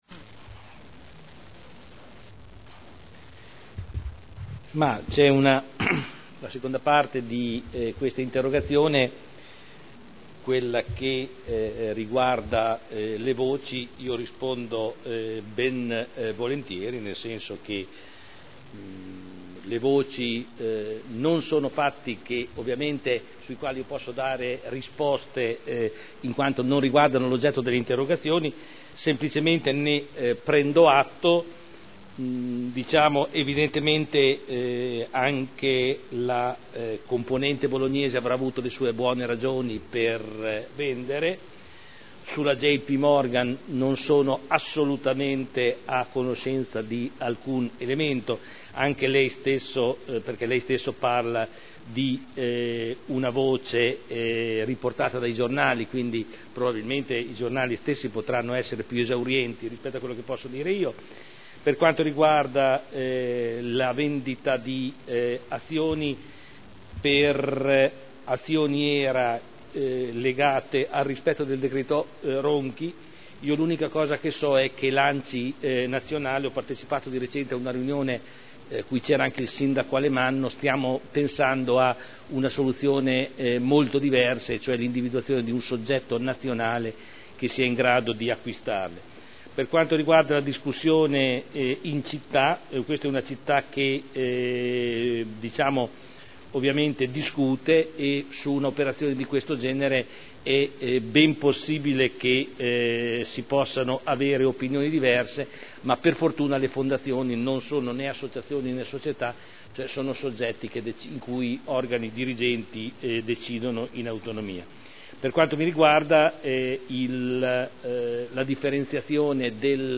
Seduta del 25/10/2010. Risponde a interrogazione del consigliere Rossi N. (Lega Nord) avente per oggetto: “Fondazione Cassa Modena”